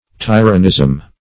Search Result for " tyronism" : The Collaborative International Dictionary of English v.0.48: Tyronism \Ty"ro*nism\, n. The state of being a tyro, or beginner.